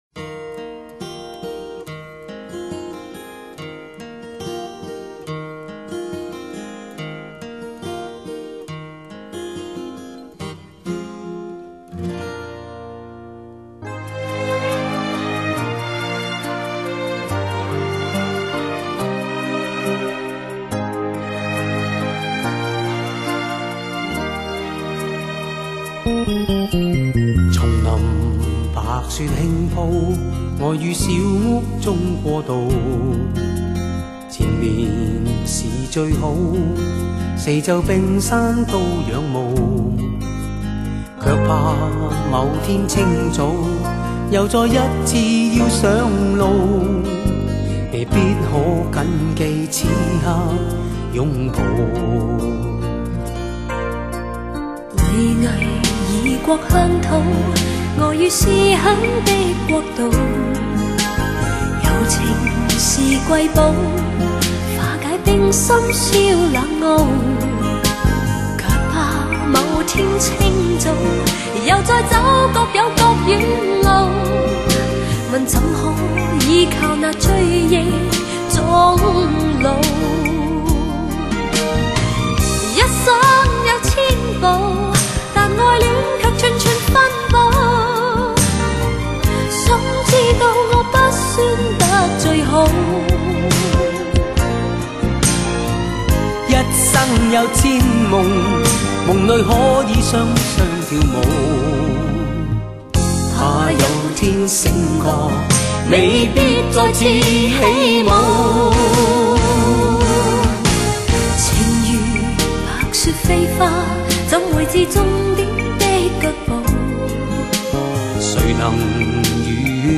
流行
香港流行乐坛80年代-90年代对唱经典作品，2CD全收录！
精选十代经典合唱情歌，首首动听肺腑，旋律优美。